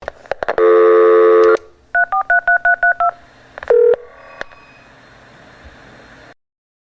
Modem Dialing for Connection
SFX
yt_w8JClXFm2AU_modem_dialing_for_connection.mp3